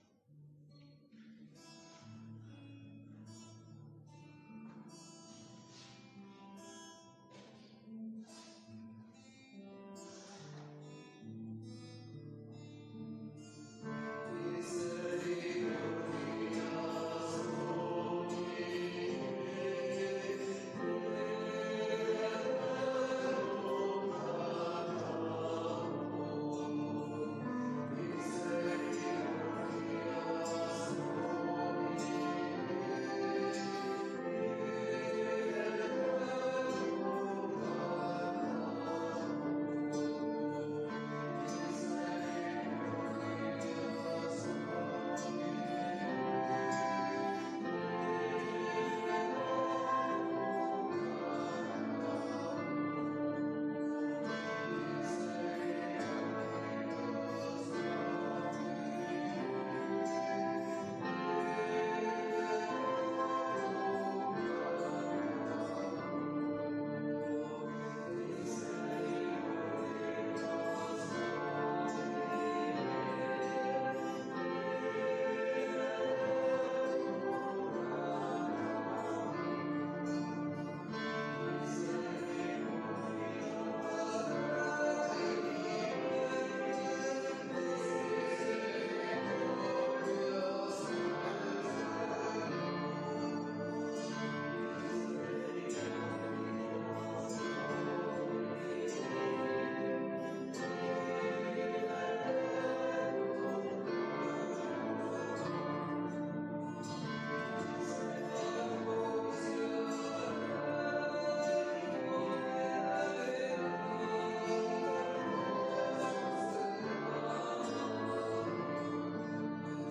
Pregària de Taizé a Mataró... des de febrer de 2001
Església de Santa Anna - Diumenge 27 d'octubre de 2019
Vàrem cantar...